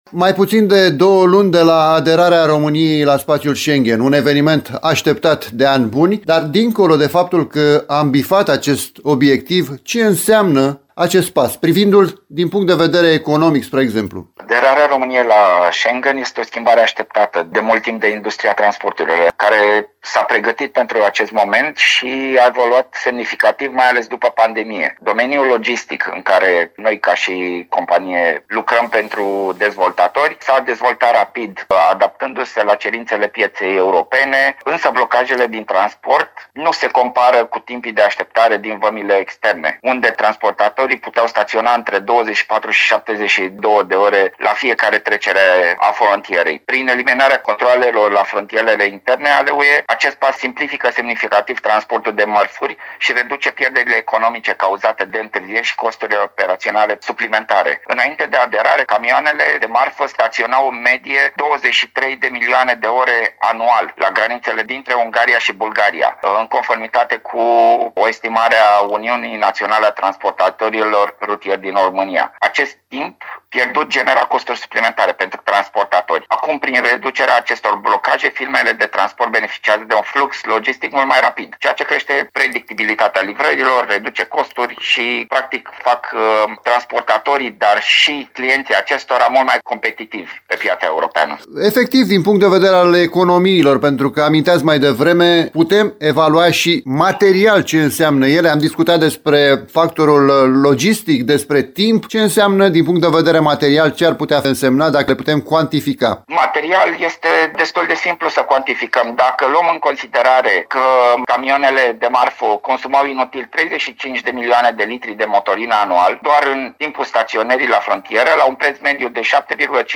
consultant în probleme de sustenabilitate.